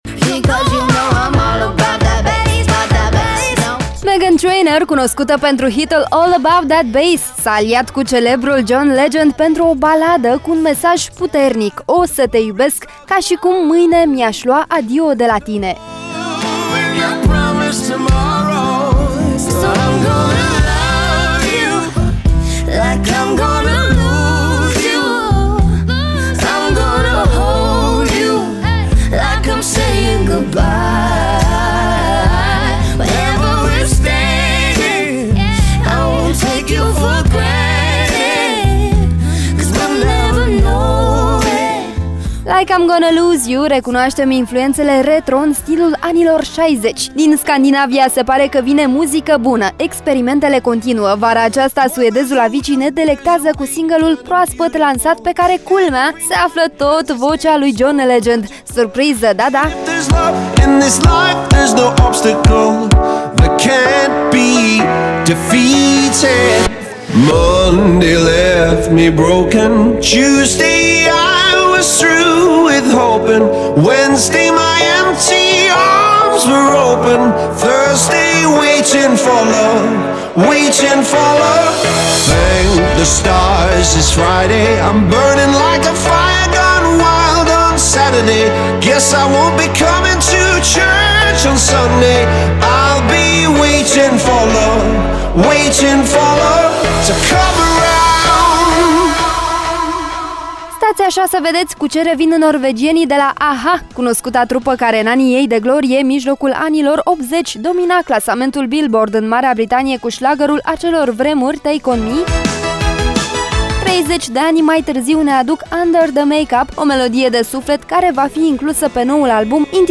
În Like I’m Gonna Lose You recunoaștem influențele retro, în stilul anilor ’60.